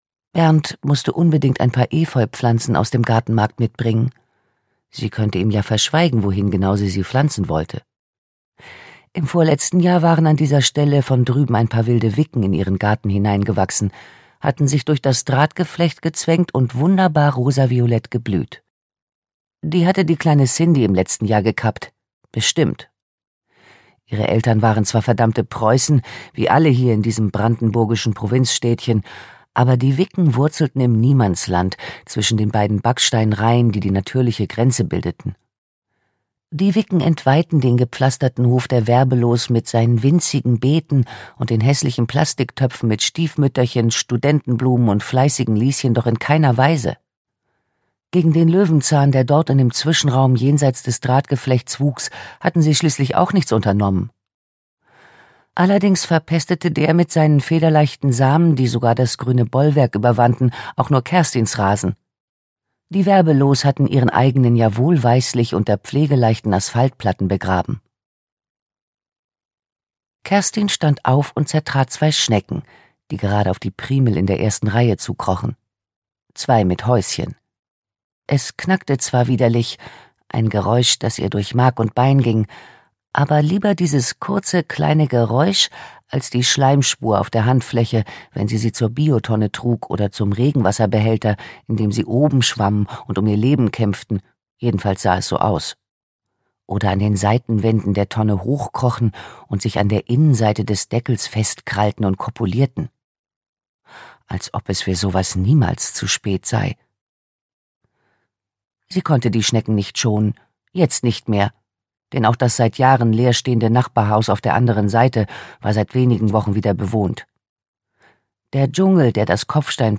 Krimi to go: Im Dickicht - Gabriele Wolff - Hörbuch